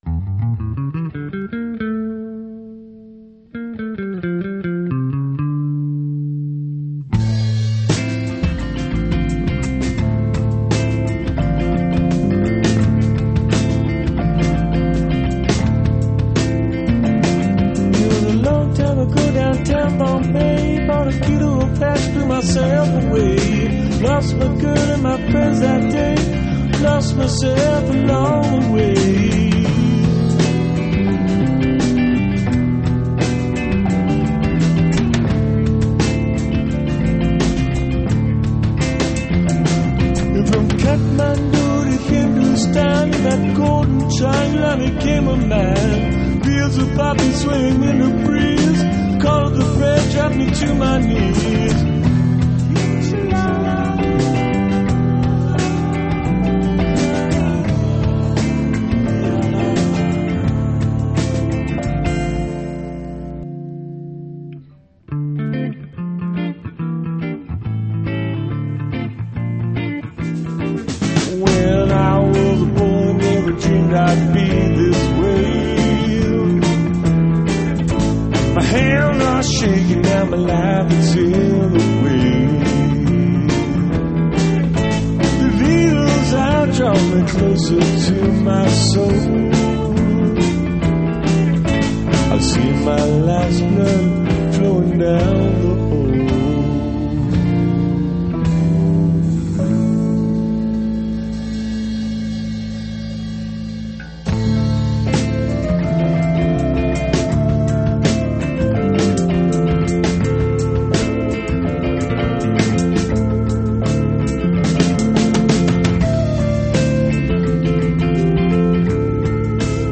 Guitars
Vocals
Bass
Drums & backup vocals
Keyboards